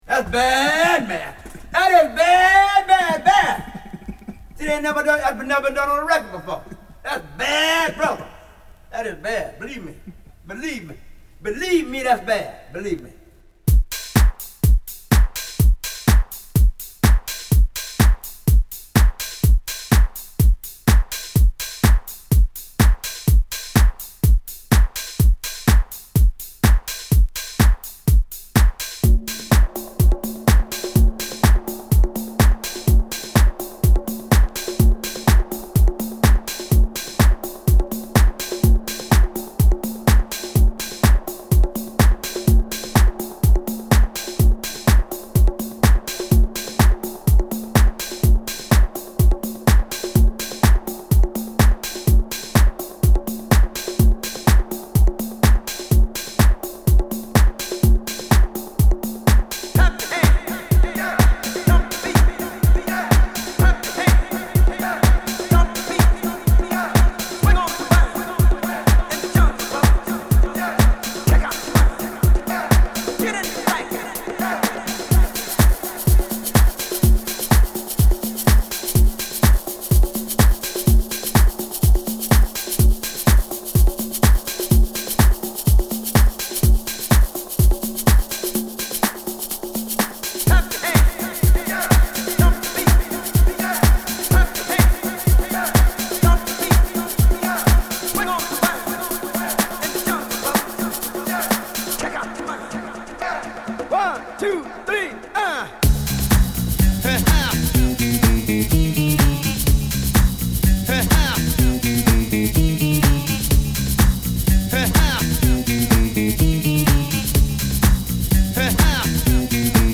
両面共にキラーなファンキーエディット！更に、パーカッションとヴォーカルだけのDJツールトラックも収録！